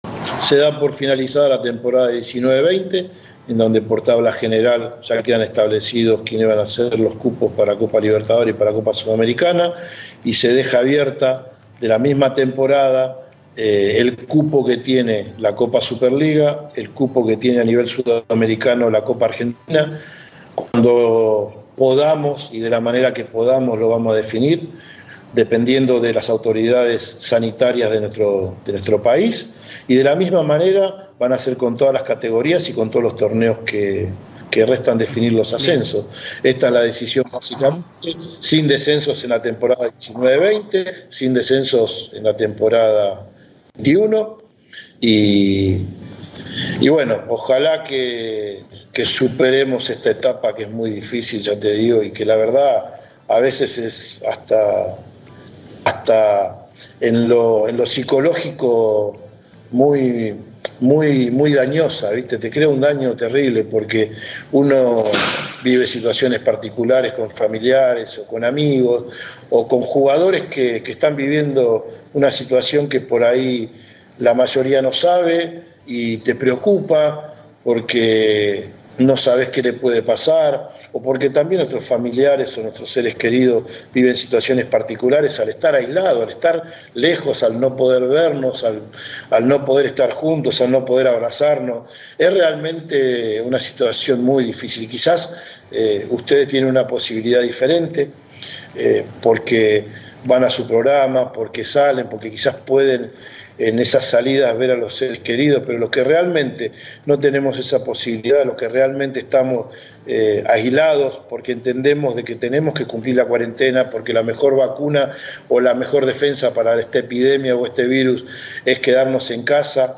(Claudio Tapia en diálogo con TNT Sports)